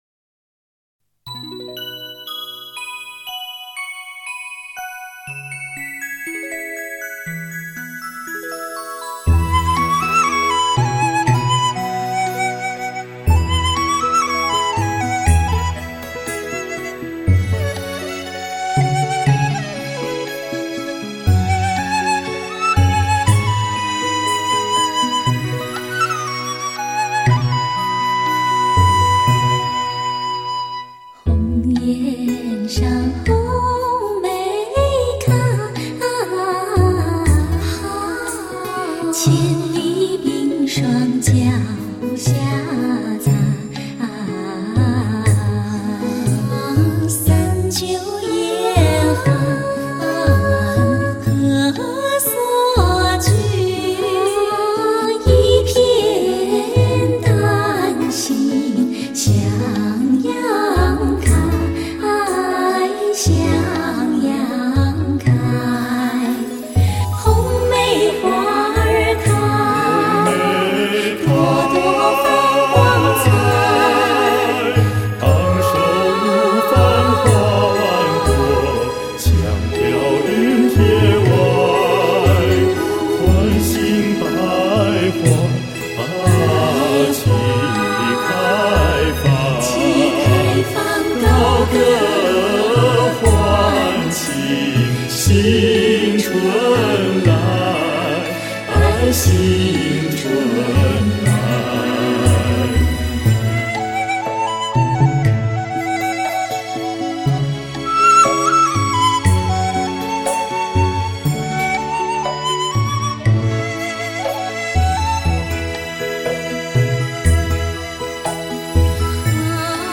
在那甜美悠扬的嗓音和专业的和音中，变的如此生机勃勃，令人陶醉。